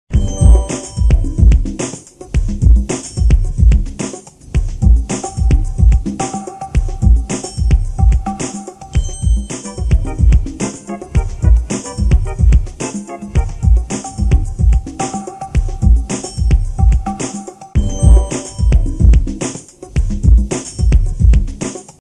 спокойные
без слов